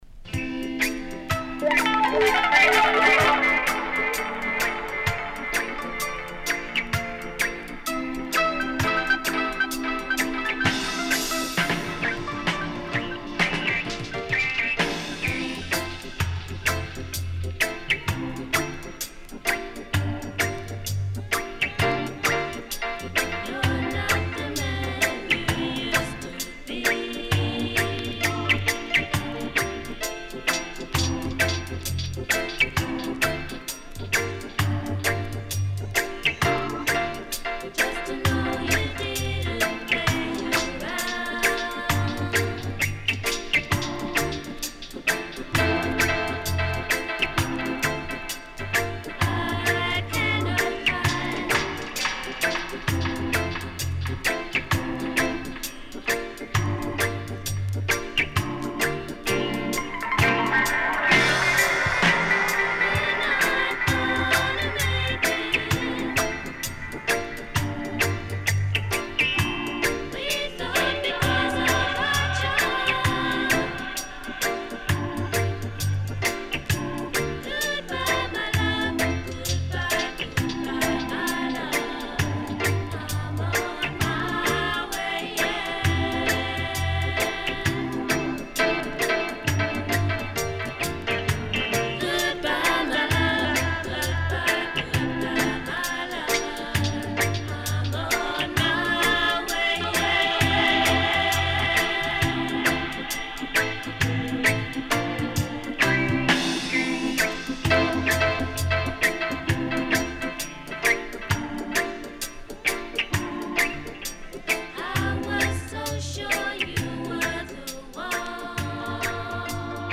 UK Lovers Rock Classic & Dubwise
SIDE A:一ヶ所傷の為少しプチ入ります。試聴で確認出来ます。